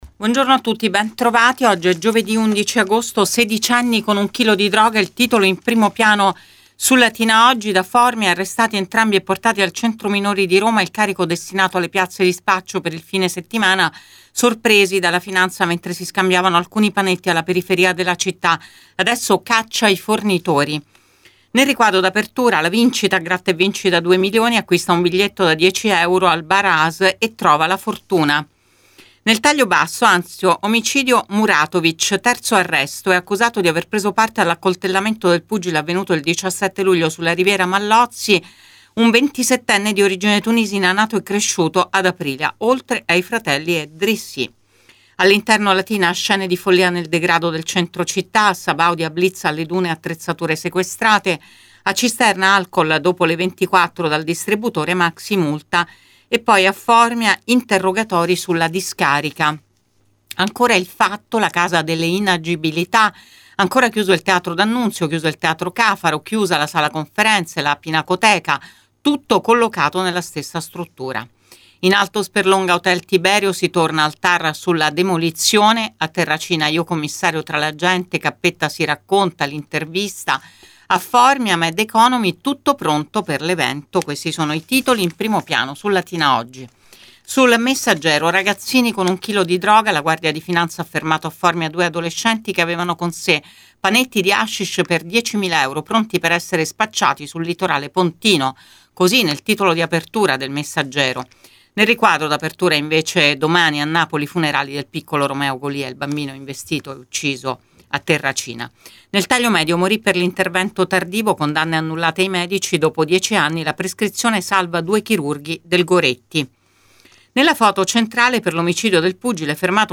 LATINA – Qui trovate Prima Pagina, ora solo in versione web, per dare uno sguardo ai titoli di Latina Editoriale Oggi e Il Messaggero Latina. (audio dopo la pubblicità)